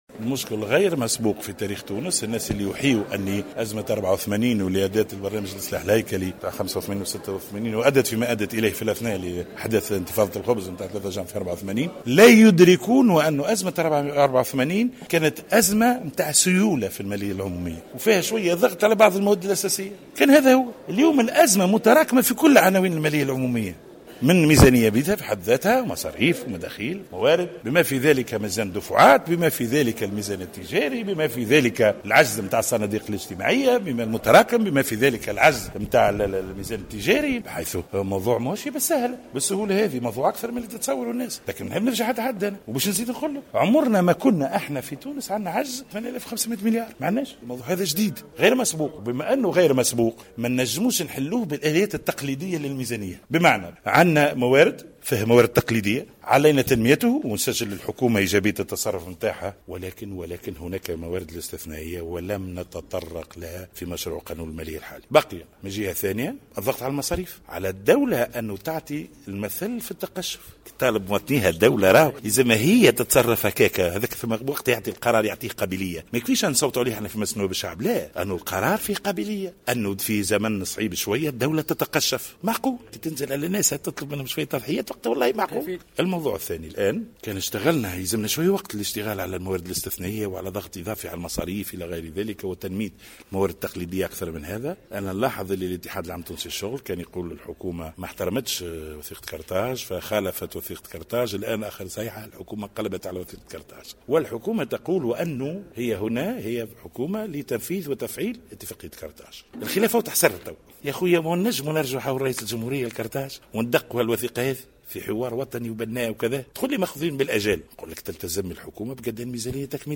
وأضاف منذر بالحاج علي، في تصريح لمراسل الجوهرة أف أم على هامش جلسة للبرلمان مناقشة ميزانية الدولة لسنة 2017 والتي تتواصل أشغالها، قال إن خطورة الوضع الاقتصادي الراهن أكبر بكثير مما يتصورها البعض، إذ لم يسبق وأن تجاوز العجز في تونس 8500 مليار، وهو ما لا يمكن معالجته بالموارد التقليدية بل إن الأمر يستدعي اللجوء إلى الموارد الاستثنائية.